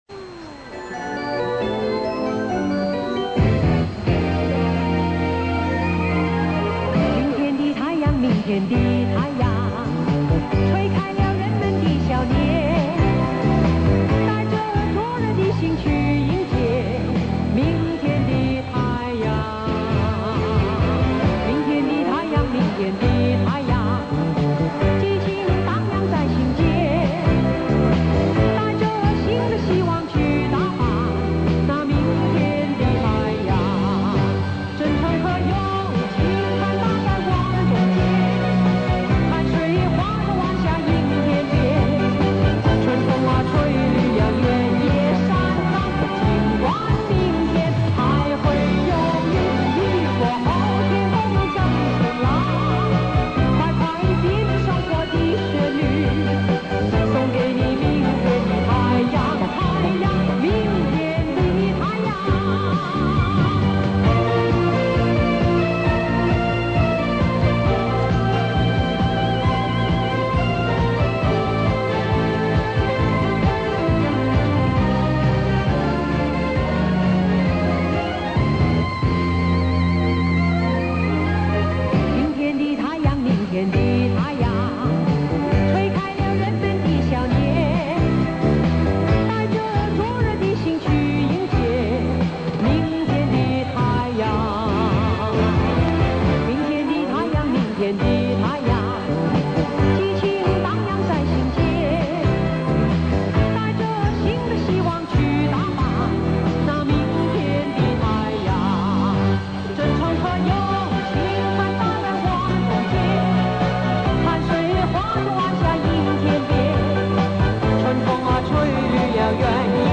这是本人录自电台的一组节目，由于录制于中波段，有些电磁干扰，见谅！
每集一小时，感觉非常珍贵：主持人声音的甜美，歌曲异常的动听，要不是结束曲的响起，你可能还沉浸在魅力老歌之旅的路途上呢？